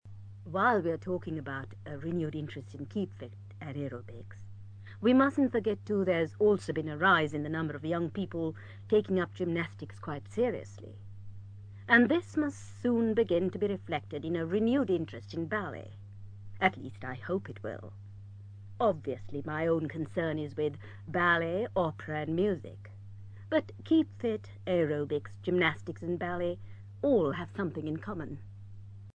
ACTIVITY 185: You are going to hear people from different professions talking about the boom in the 'Keep-Fit' industry.